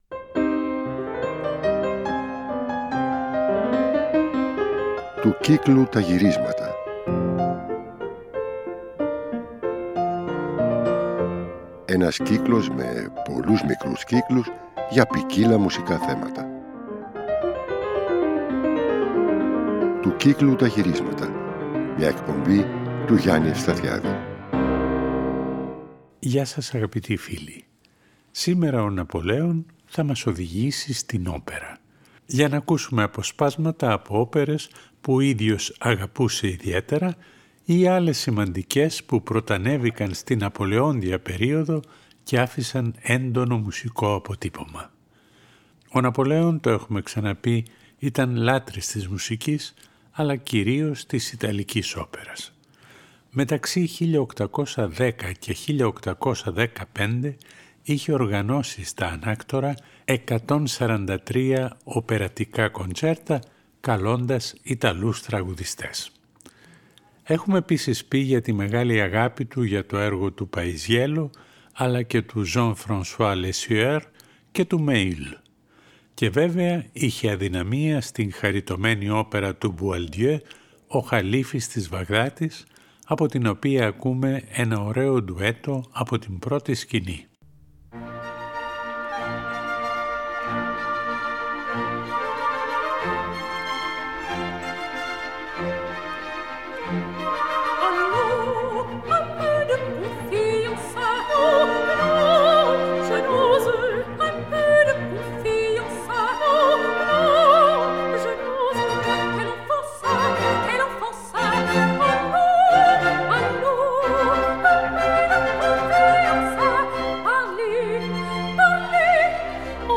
Θα ακουστούν αποσπάσματα από όπερες του Boieldieu, του Cimarosa, του Spontini.